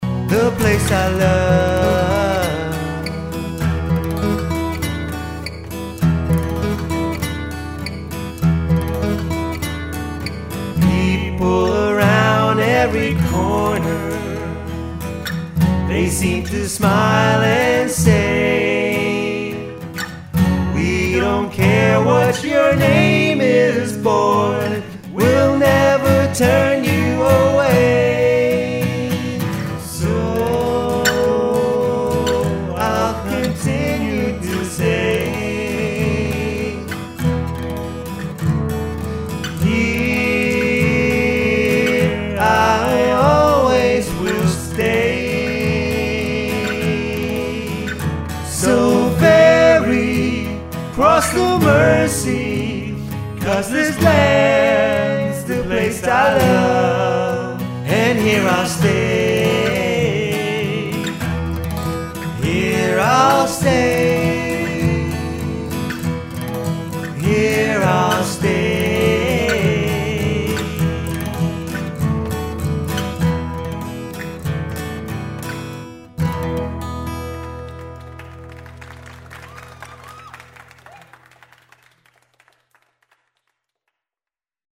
A Vocal and Acoustic Instrument Duo
on accoustic instruments
(live) Mercy Mercy